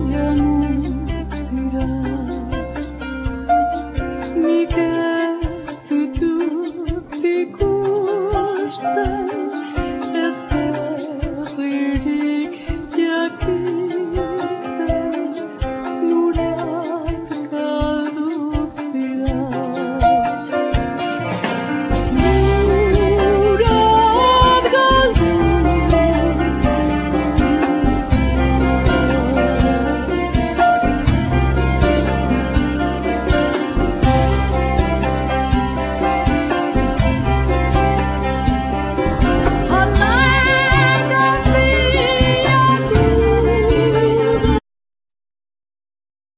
Piano,Accordion
Double bass
Drums
Percussion
Saxophone
Acoustic and electric guitar
Voice
Txalaparta